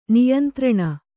♪ niyantraṇa